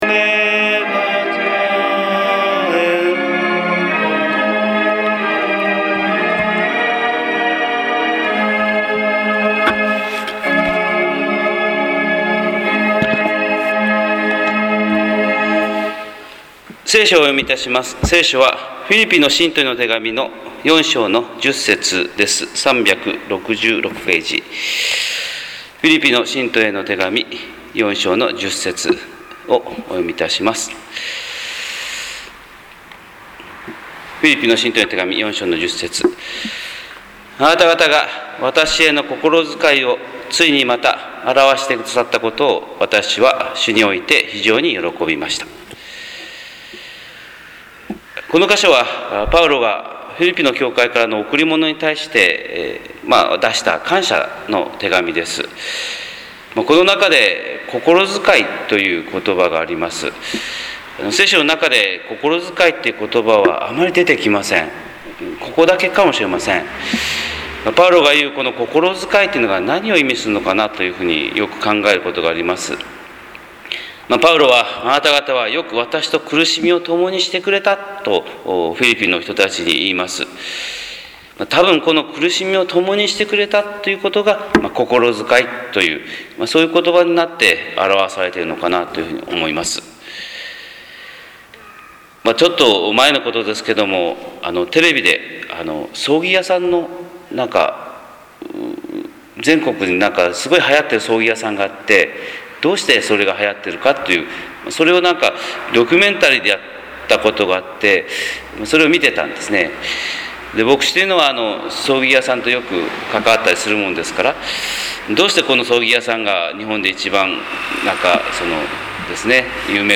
日本福音ルーテル教会（キリスト教ルター派）牧師の朝礼拝説教です！
朝礼拝120629